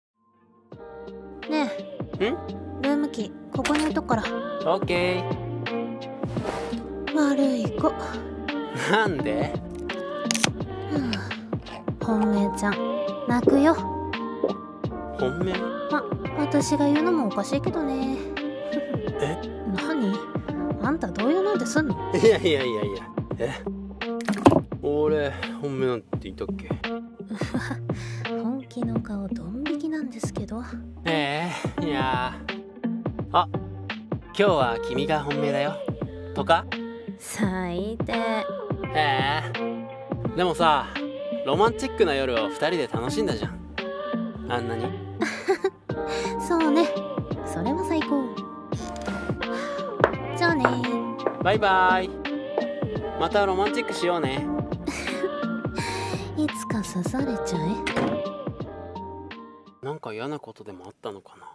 【声劇】クズ的ロマンチック論